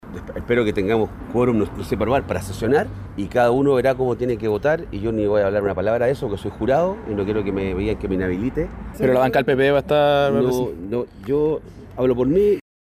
El también PPD, Ricardo Lagos Weber, dijo que asistirá hoy y preguntado por otros senadores de su bancada, dice que solo puede hablar por él.